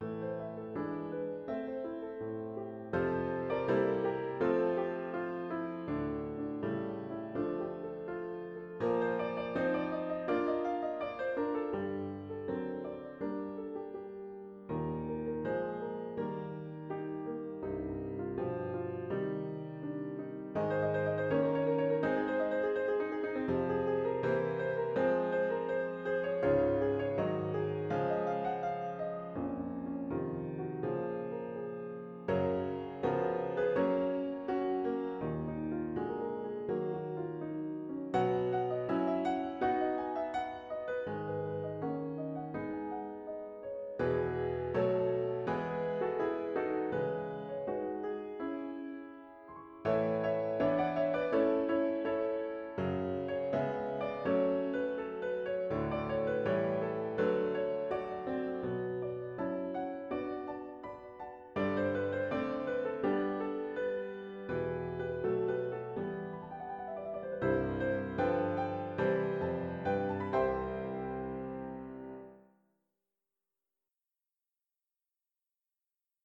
für Klavier